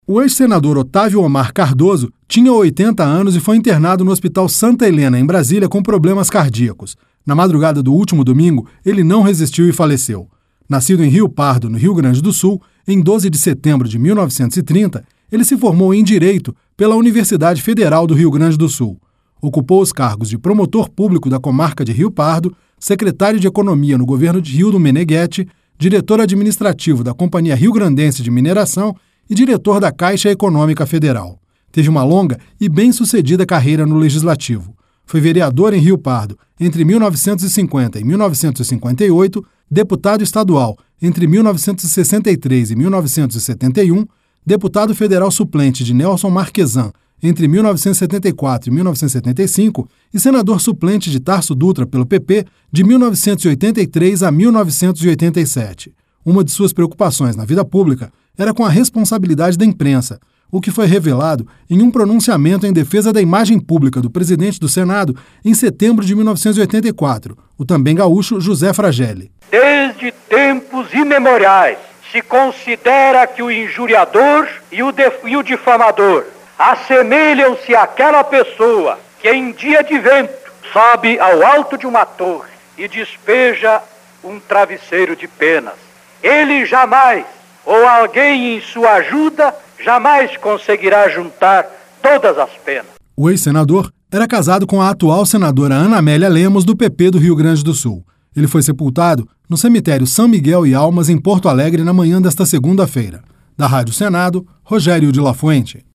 Pronunciamento